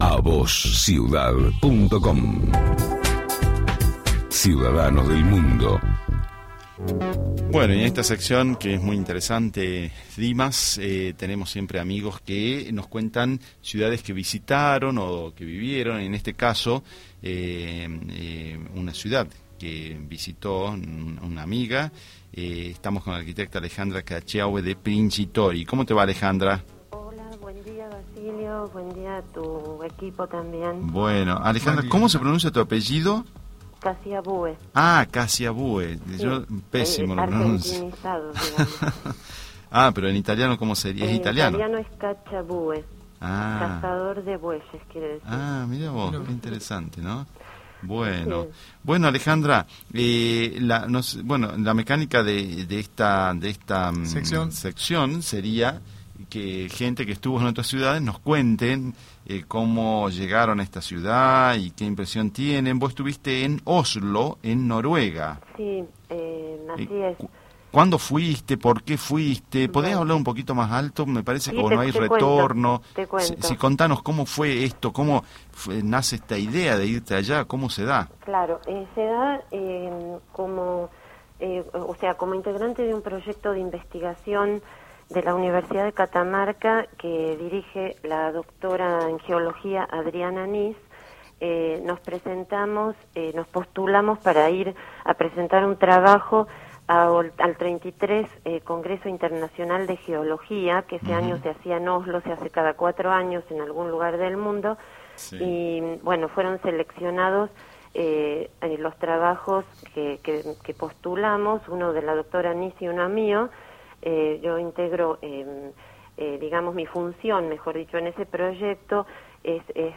Ciudadanos del Mundo AUDIO DE LA ENTREVISTA